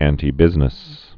(ăntē-bĭznĭs, ăntī-)